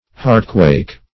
Meaning of heartquake. heartquake synonyms, pronunciation, spelling and more from Free Dictionary.
Search Result for " heartquake" : The Collaborative International Dictionary of English v.0.48: Heartquake \Heart"quake`\ (-kw[=a]k`), n. Trembling of the heart; trepidation; fear.